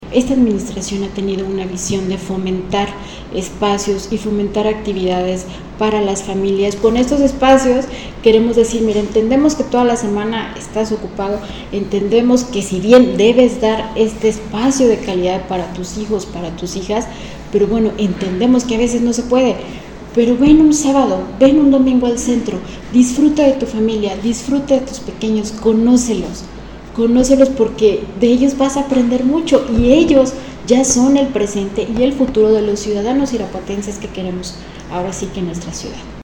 AudioBoletines
Liliana Torrero García, directora del DIF